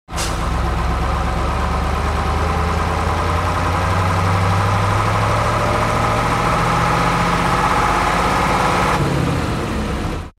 دانلود صدای کامیون 8 از ساعد نیوز با لینک مستقیم و کیفیت بالا
جلوه های صوتی